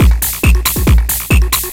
DS 138-BPM A1.wav